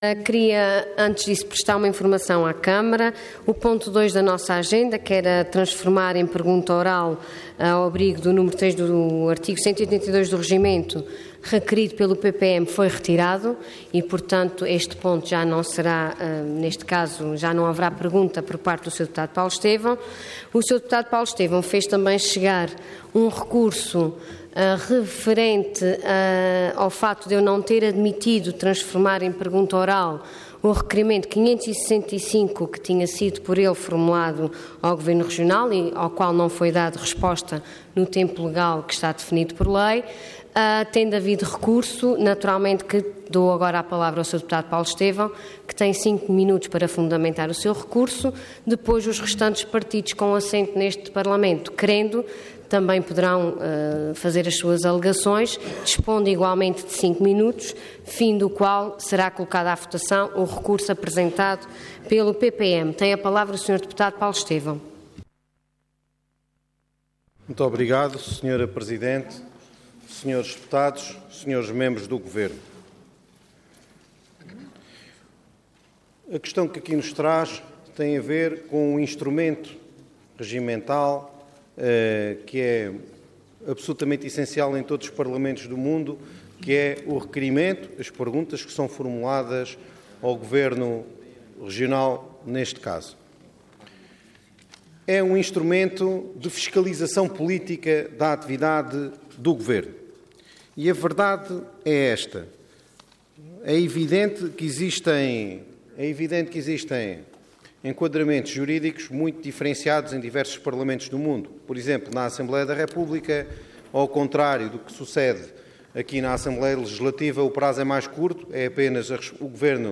Intervenção
Orador Paulo Estêvão Cargo Deputado